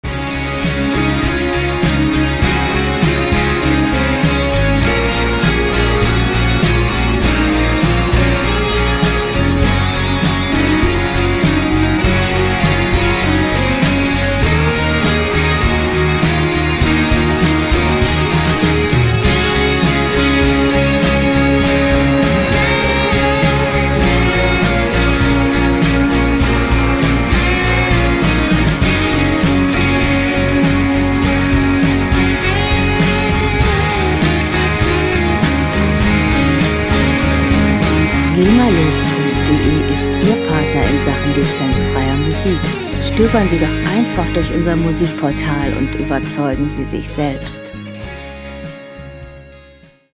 Rockmusik - Rockkonzert
Musikstil: Rock
Tempo: 100 bpm
Tonart: Des-Dur
Charakter: positiv, gemeinschaftlich
Instrumentierung: E-Gitarre, E-Bass, Drums, Vocals